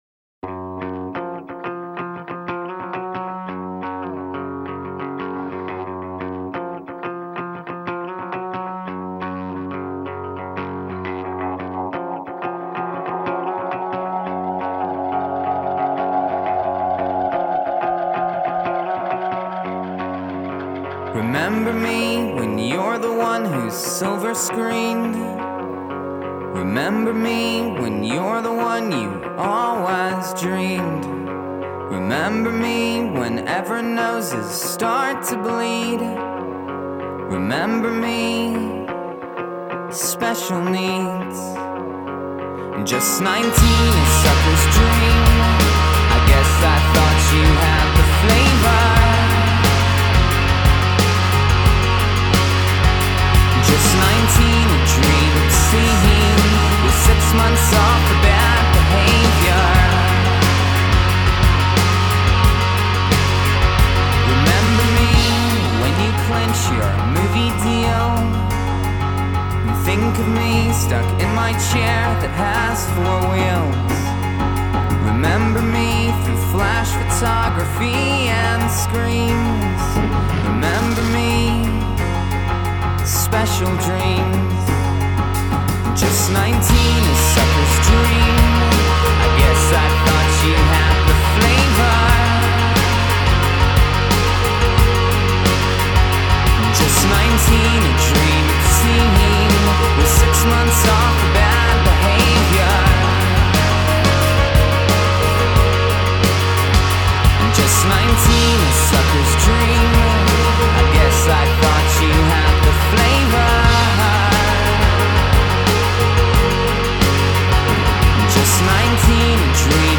Alternative rock Indie rock Rock